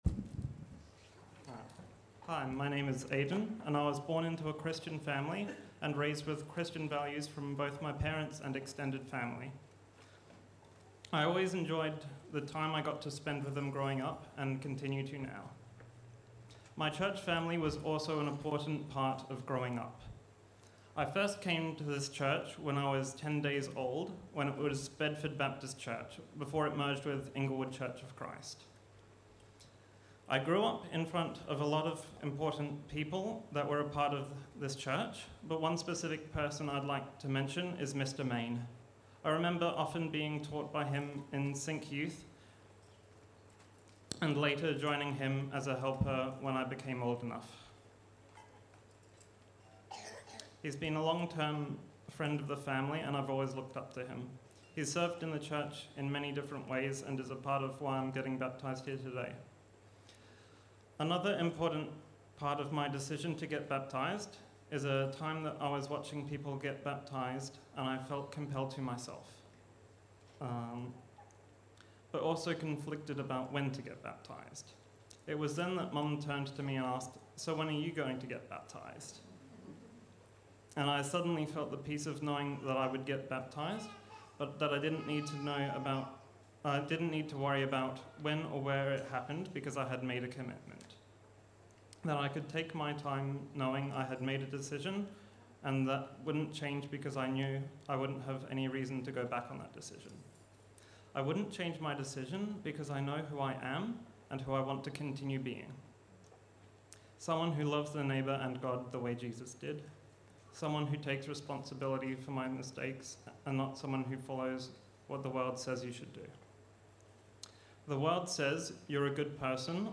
Baptism-Sunday.mp3